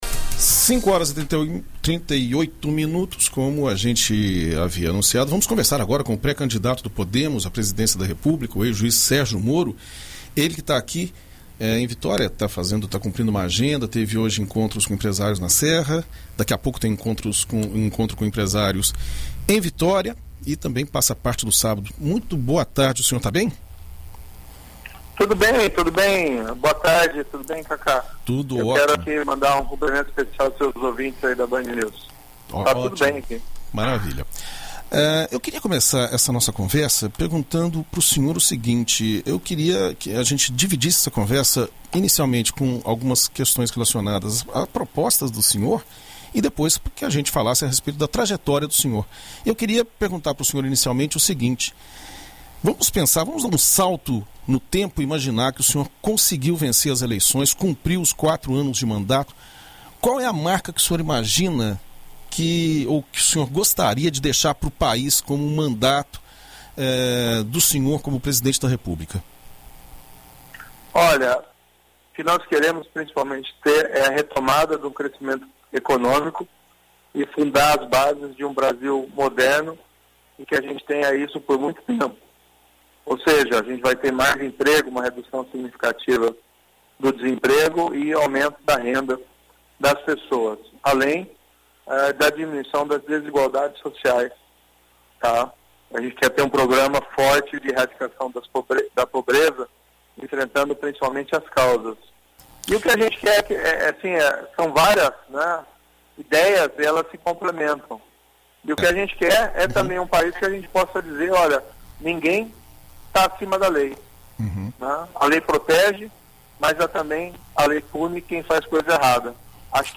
Em entrevista à BandNews FM Espírito Santo, o pré-candidato apresentou propostas nas áreas da economia para diminuir a desigualdade social e geração de emprego. O ex-juiz também comentou sobre outros temas relevantes para o país, como educação, e abordou temas polêmicos como a Operação Lava Jato.